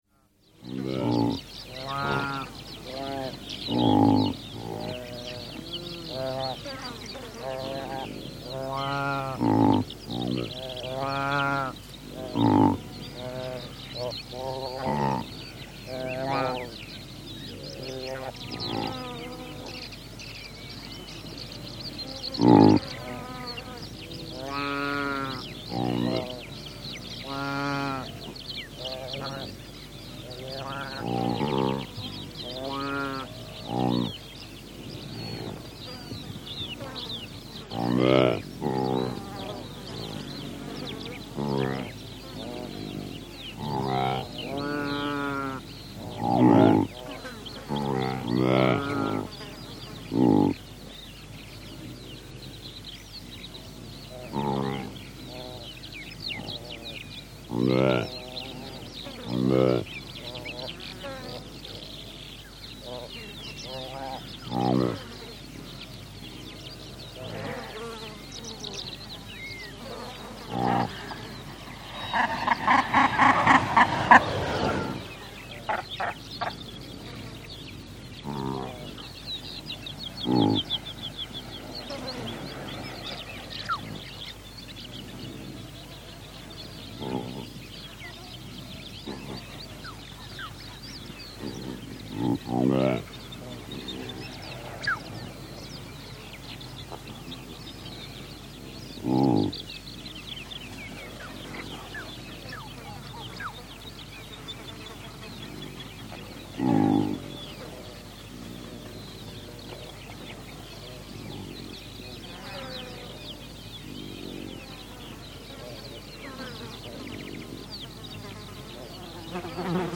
Записи сделаны в дикой природе и передают атмосферу саванны.
Сайга с теленком в Казахстане